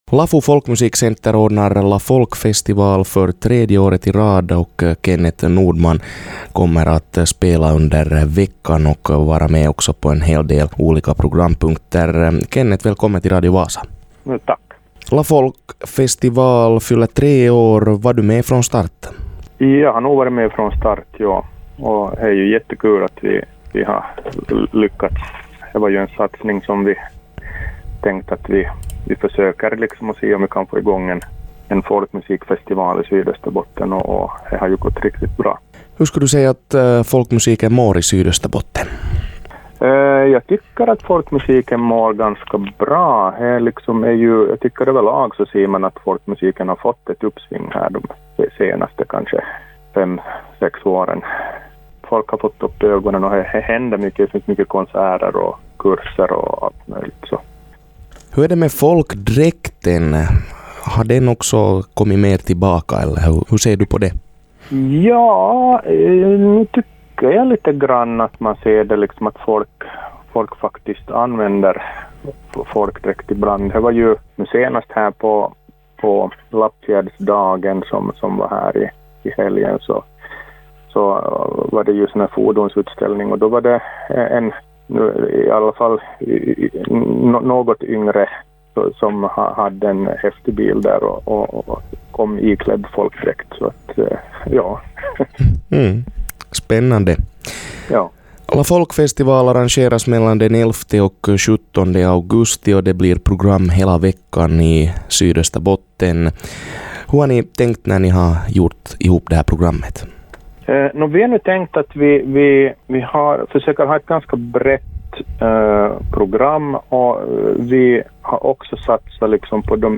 ringde upp